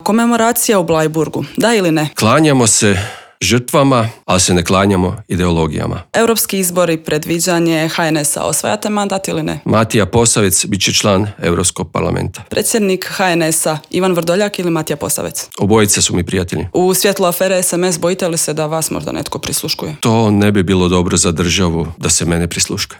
ZAGREB - Gost Intervjua tjedna Media servisa bio je ministar graditeljstva Predrag Štromar.